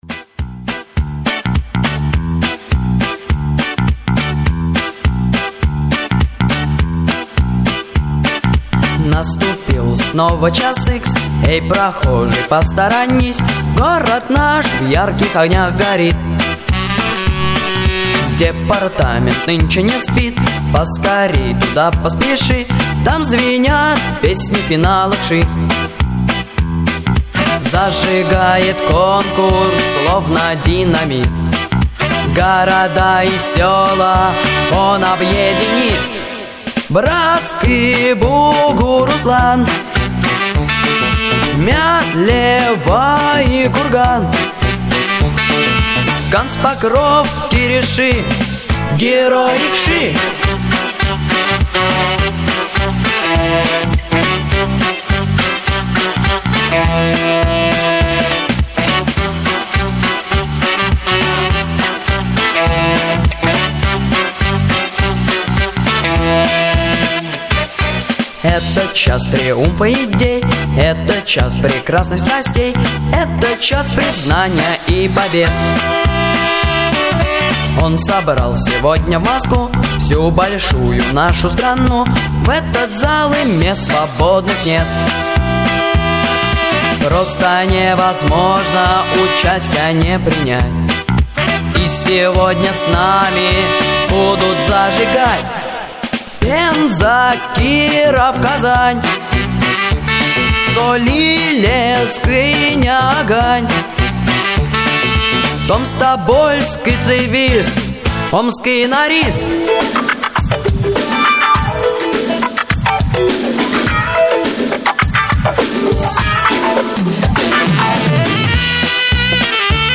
Фирменная песня Конкурса   Прослушать минусовку